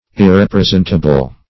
Search Result for " irrepresentable" : The Collaborative International Dictionary of English v.0.48: Irrepresentable \Ir*rep`re*sent"a*ble\, a. Not capable of being represented or portrayed.
irrepresentable.mp3